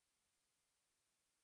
silent.ogg